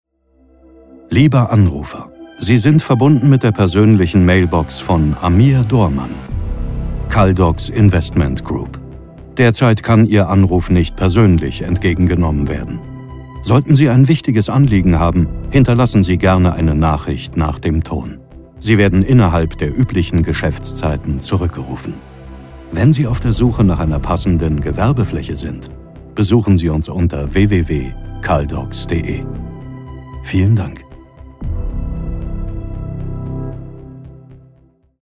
Telefonansagen mit Jack-Sparrow-Stimme